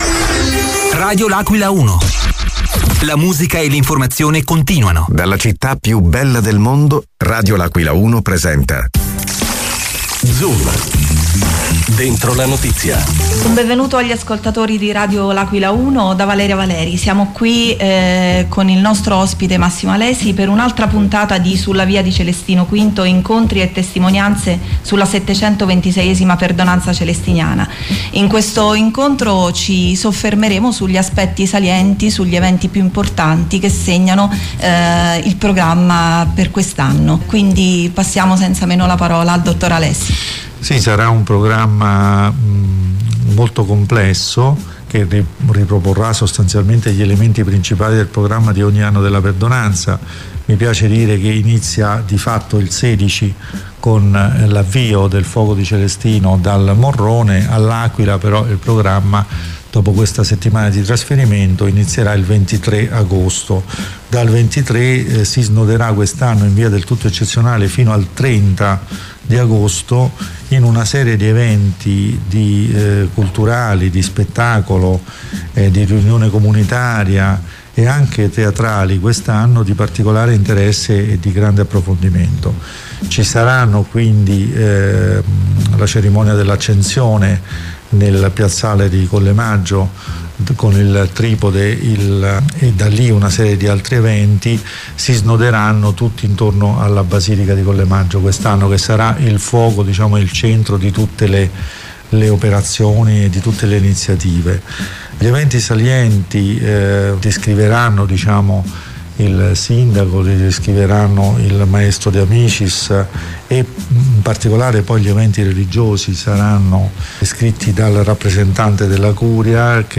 negli studi di Radio L’Aquila 1